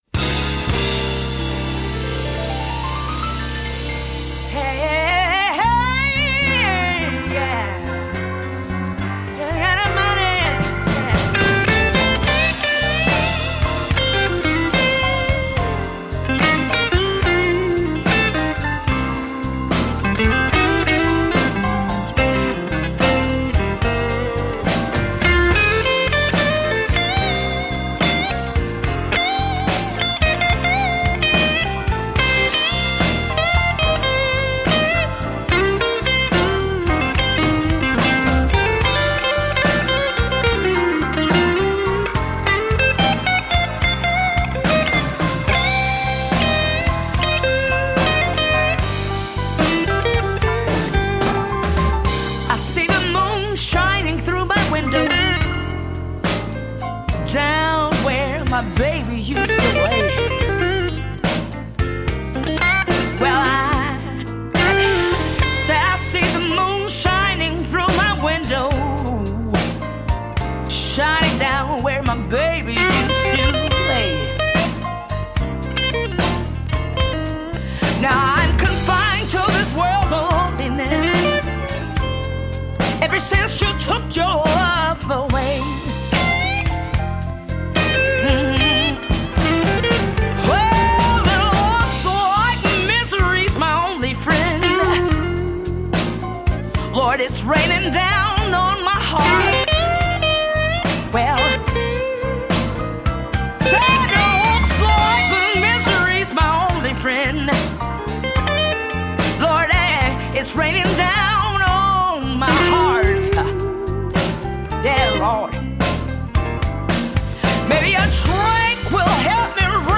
Blues guitar legend in the making.